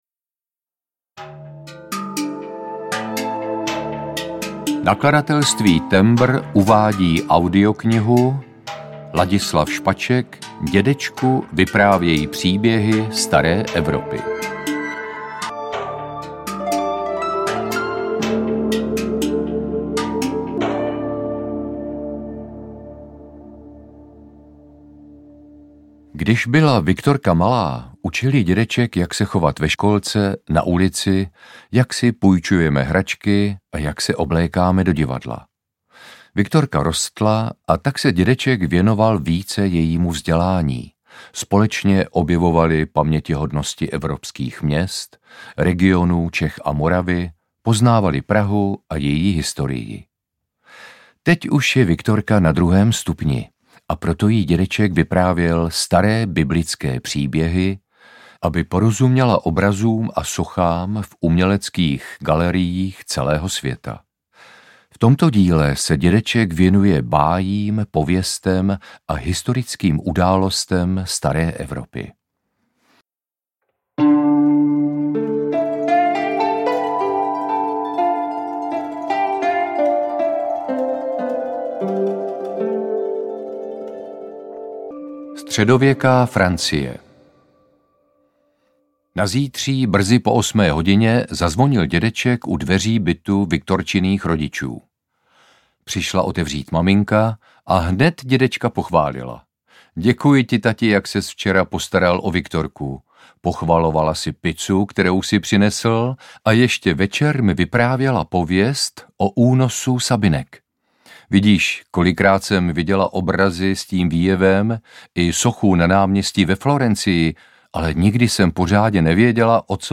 Ukázka z knihy
• InterpretLadislav Špaček
dedecku-vypravej-pribehy-stare-evropy-audiokniha